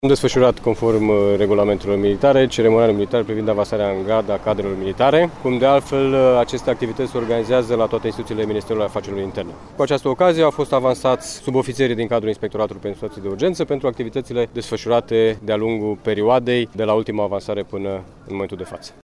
Din cauza ploilor abundente, a concentrării scurgerilor de pe versanţi şi a vântului puternic din zilele de 23 şi 24 octombrie au fost afectate mai multe localităţi din Caraş-Severin şi s-au înregistrat pagube a căror valoare depășește 500 de mii de lei, a declarat prefectul Silviu Hurduzeu, preşedintele Comitetului: